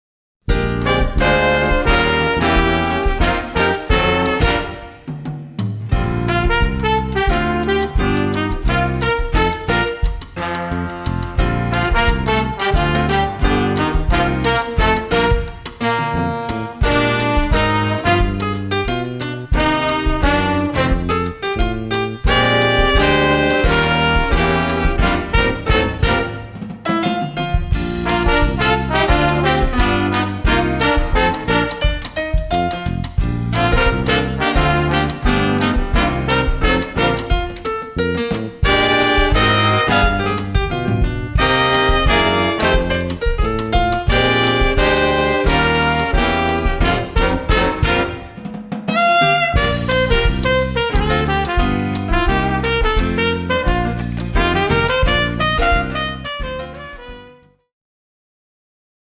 catchy Latin tune
Jazz Band